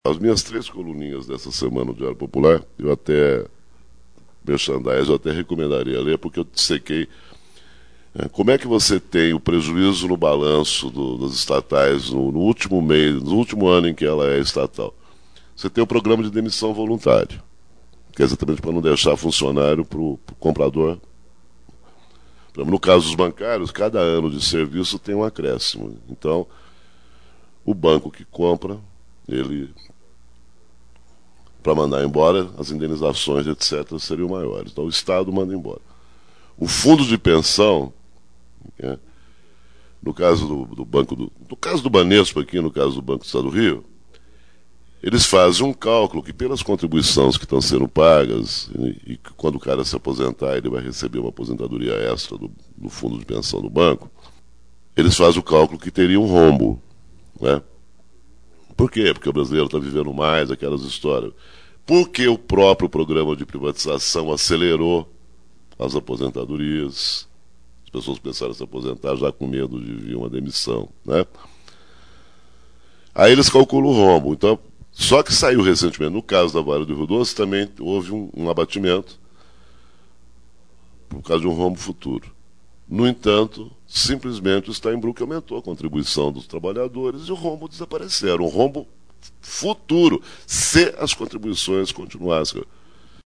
Uma delas é a operação para “limpar” as contas da estatal para a privatização, como os programas de demissão voluntária. Esse trecho está em uma palestra na Oboré, em 1999, dentro do curso Repórter do Futuro.
Abaixo, ouça trecho do áudio da palestra.